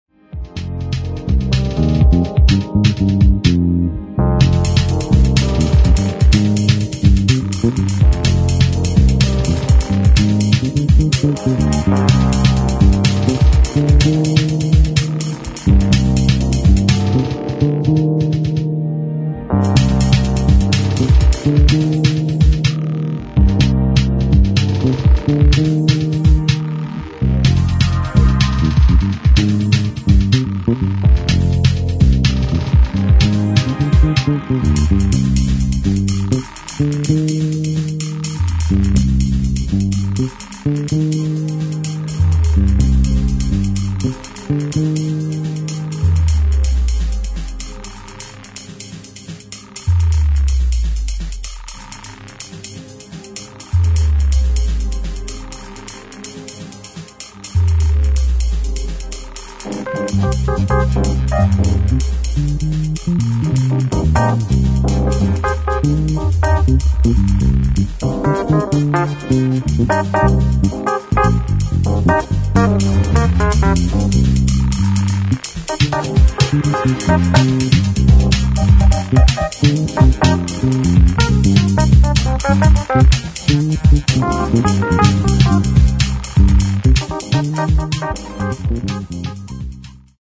[ DEEP HOUSE / JAZZ / CROSSOVER ]